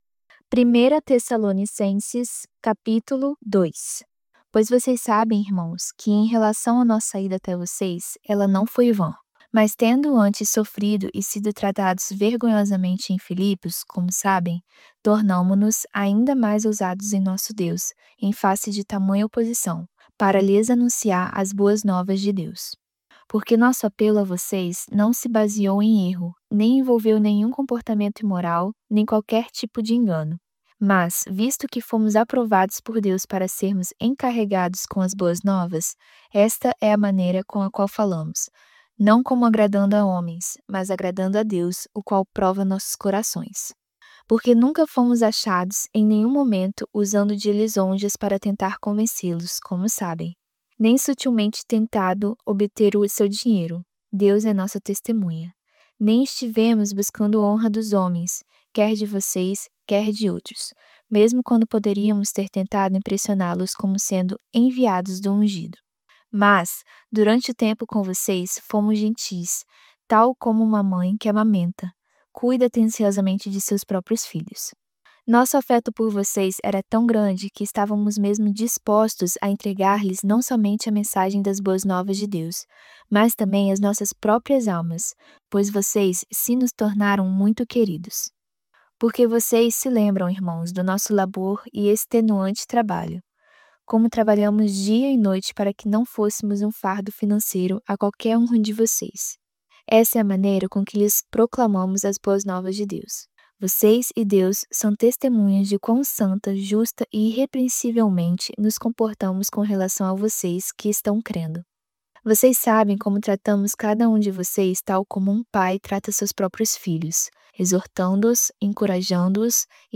voz-audiobook-novo-testamento-vida-do-pai-primeira-tessalonicenses-capitulo-2.mp3